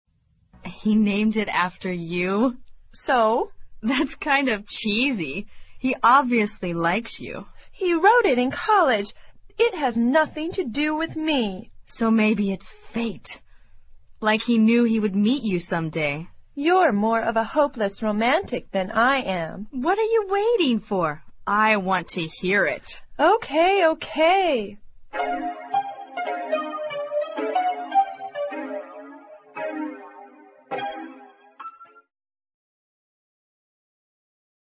《网络社交口语对话》收集了众多关于网络社交的口语对话，对提高你的口语大有用处，值得你收藏。